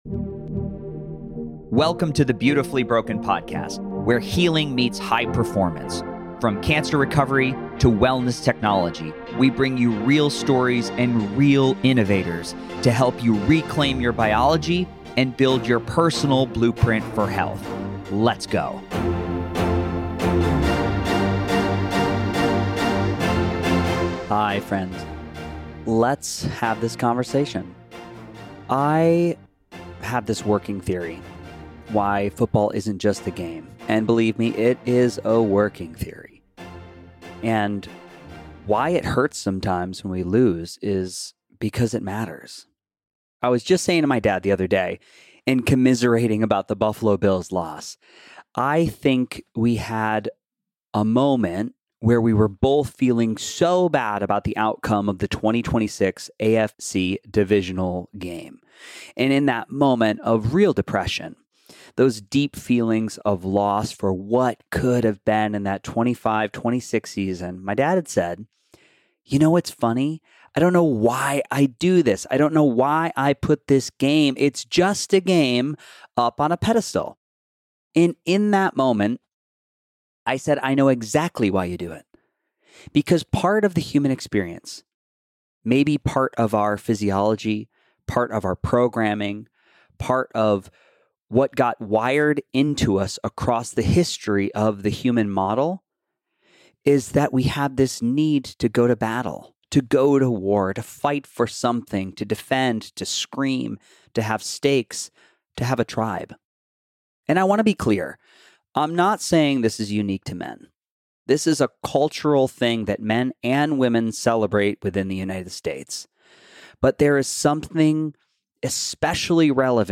In this special solo episode, I reflect on the surprising, emotional power of football—not just as entertainment, but as a vehicle for emotional expression, tribal connection, and generational bonding. Using the recent Buffalo Bills playoff loss as a launch point, I explore why sports hurt when we lose, why they matter when we win, and how something so seemingly trivial can touch the deepest parts of our humanity.